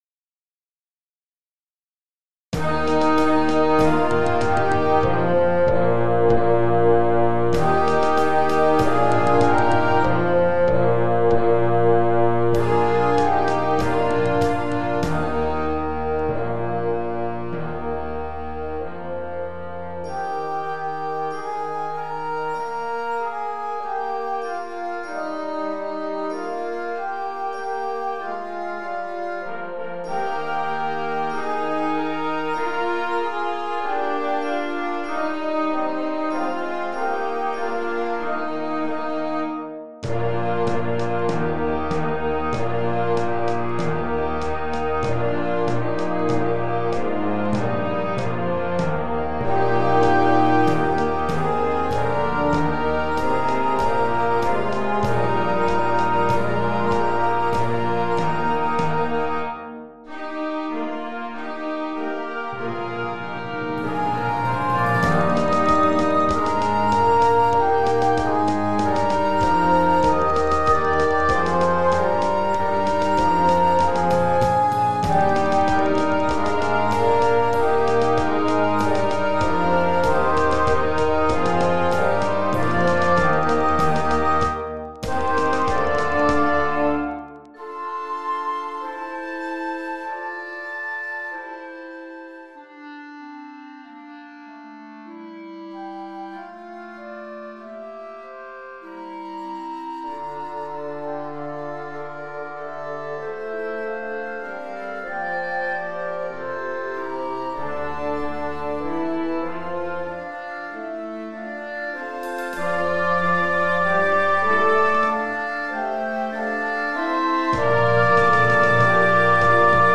Concert Band Grade 2